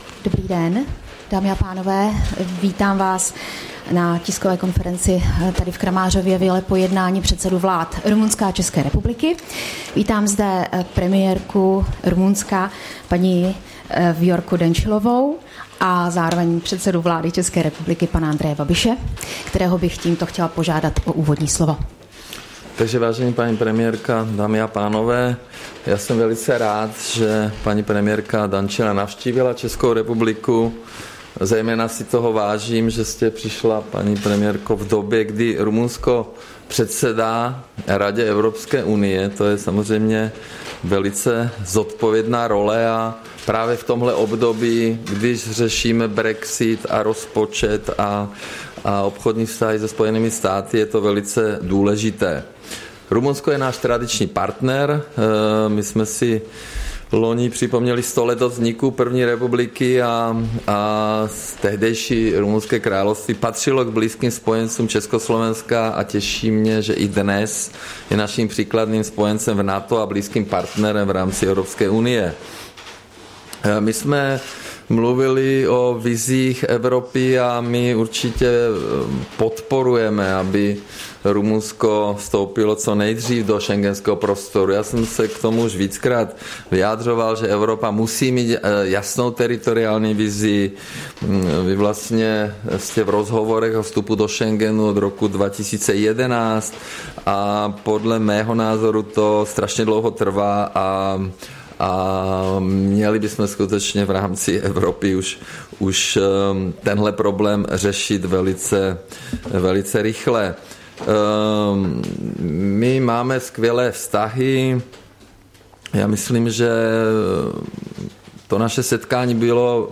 Tisková konference premiéra Andreje Babiše s rumunskou premiérkou Vioricou Dancilaovou, 15. března 2019